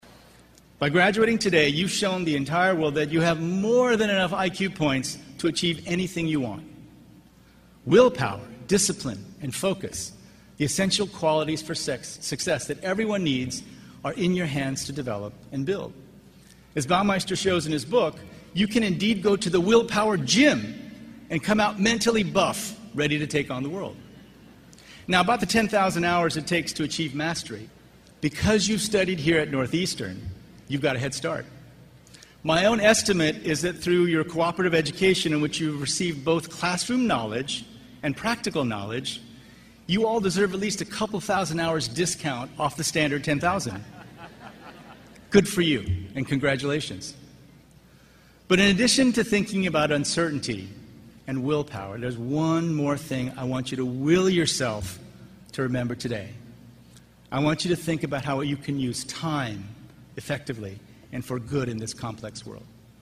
公众人物毕业演讲 第69期:金墉美国东北大学(8) 听力文件下载—在线英语听力室